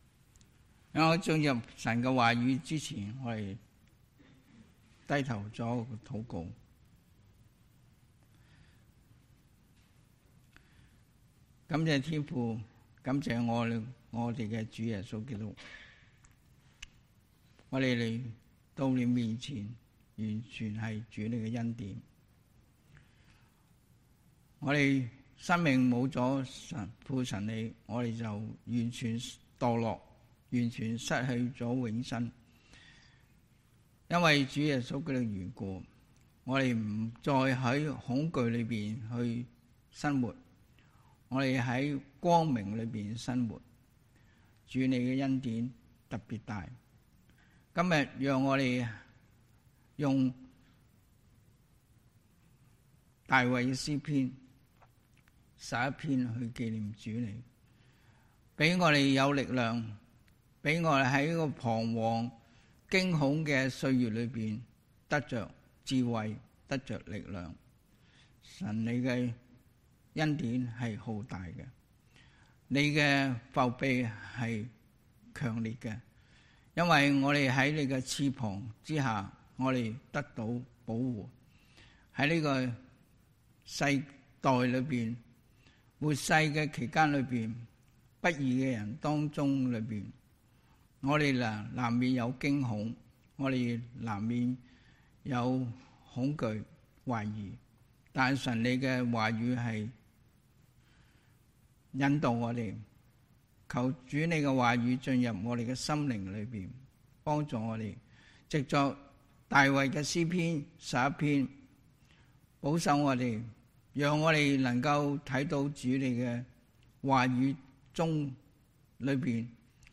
2022 恐懼中的考驗 Preacher